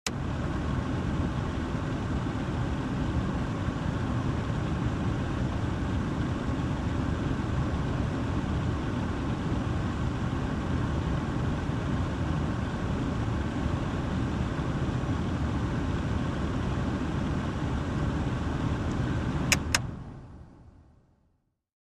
Ford LTD interior point of view as A/C runs at low then high speed. Vehicles, Sedan Air Conditioner